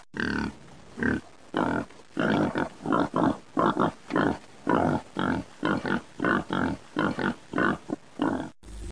Kategori Dyr